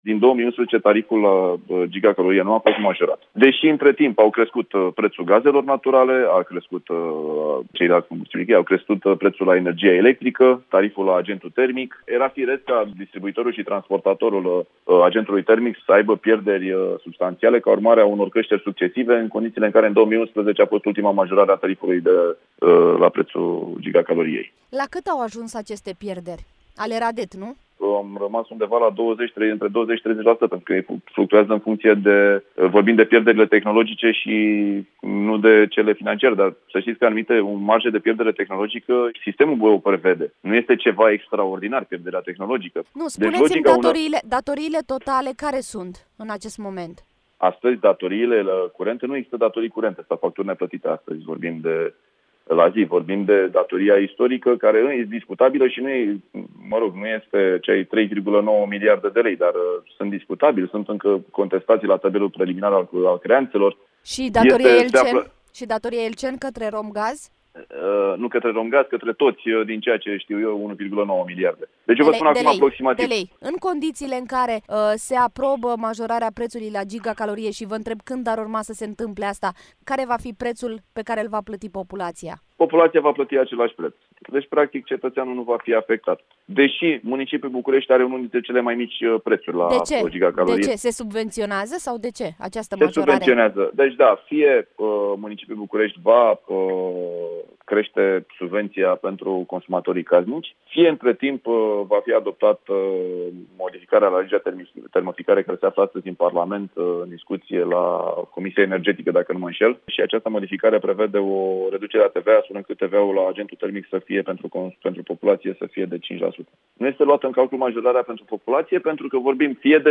Într-o declarație pentru Europa FM, administratorul public al Capitalei, Sorin Chiriță, spune că majorarea va fi suportată fie din bugetul public, printr-o subvenție mai mare pentru încălzire, fie prin reducerea TVA la agentul termic.
25-aug-INTERVIU-SORIN-CHIRITA-PENTRU-SITE-.mp3